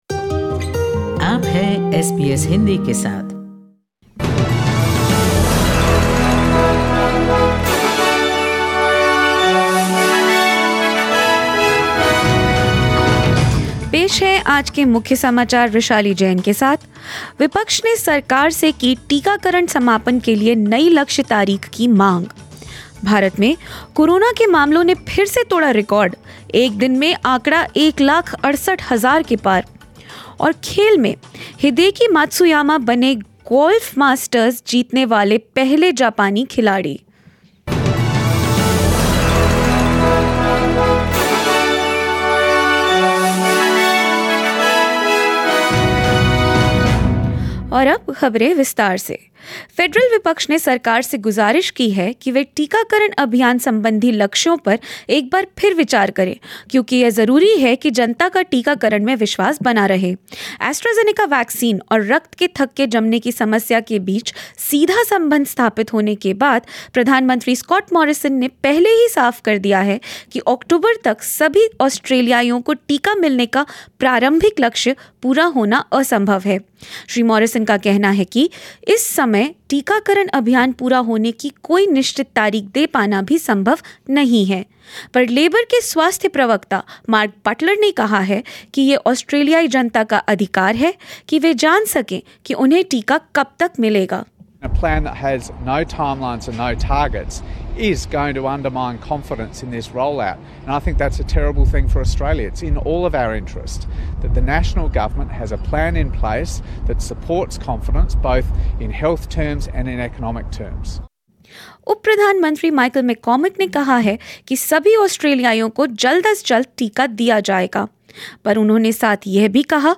News in Hindi: Supreme Court of India to hear new plea on alleged Rafale corruption after 2 weeks